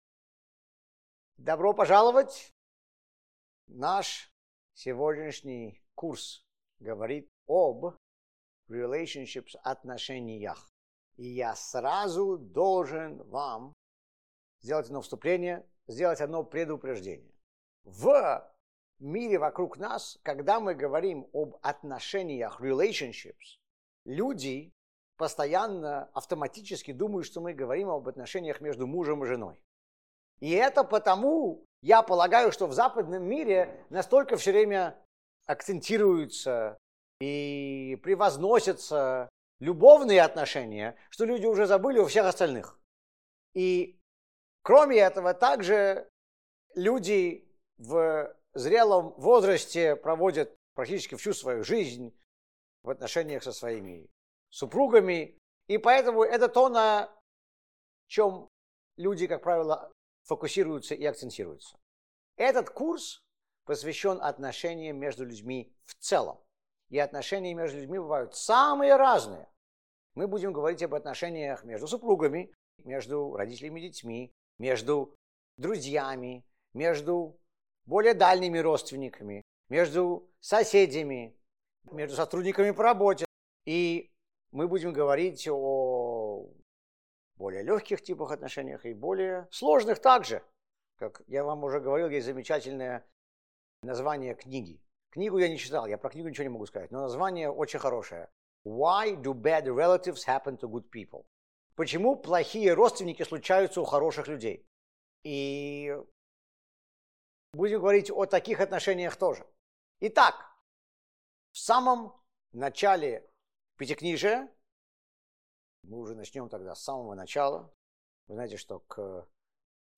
Присоединяйтесь к нам на шести лекциях на английском языке и на русском языке.